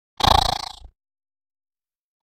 Spider_Voice.ogg